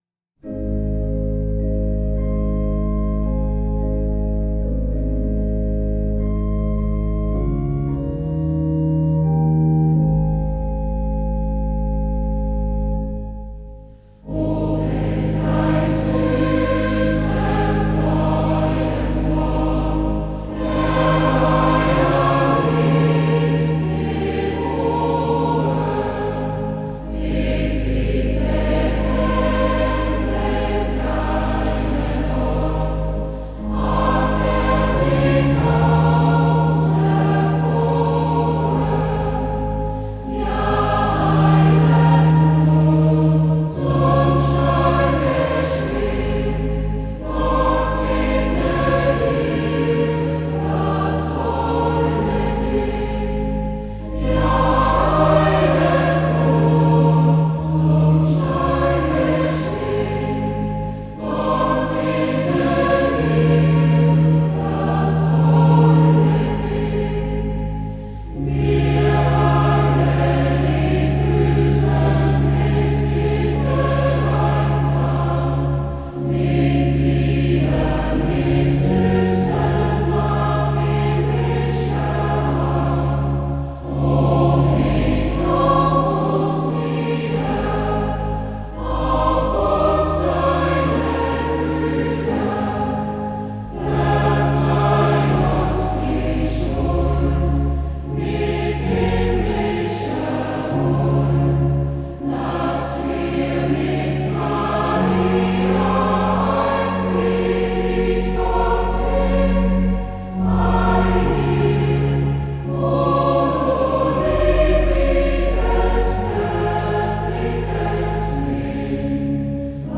Kirchenchor
HILLINGER die heute noch sehr gern gesungenen und beliebten Weihnachtslieder „O welch ein süßes Freudenswort“